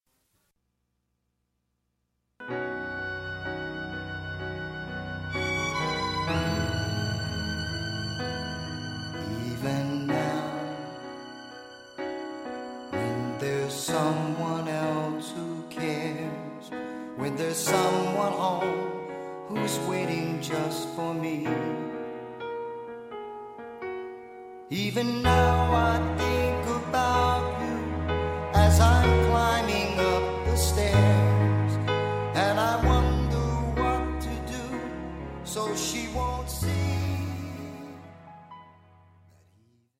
Recueil pour Mélodie/vocal/piano (MVP)